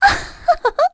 Erze-Vox_Happy1_kr.wav